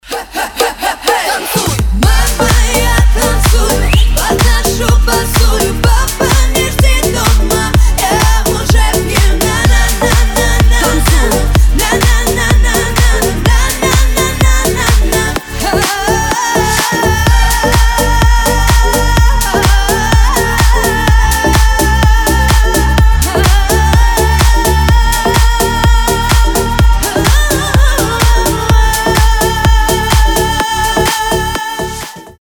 Рингтоны ремиксы , громкие рингтоны
Club house , Ритмичные
Клубные